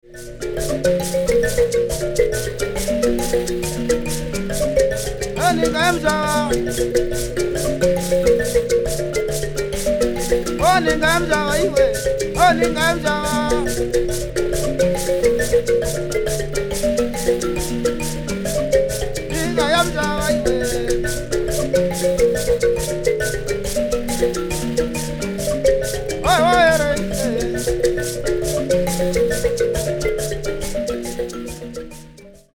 on hosho.